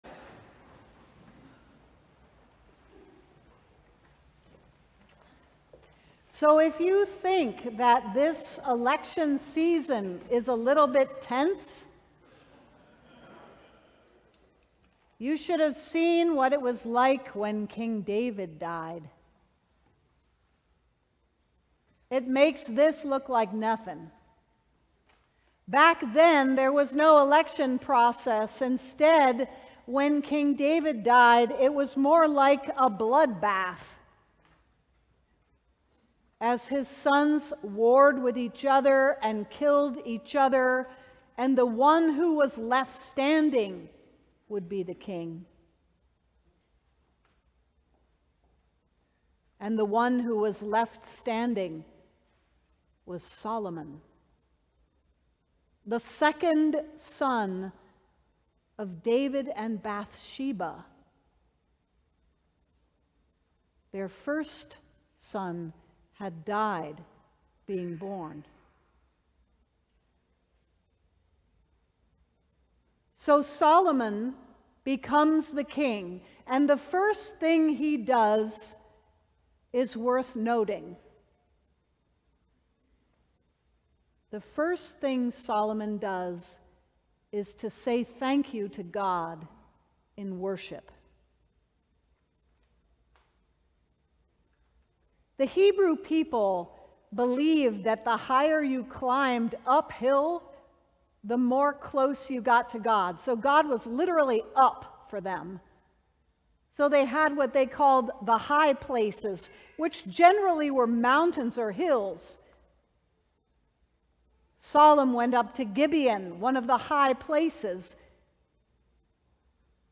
Sermons from St. John's Cathedral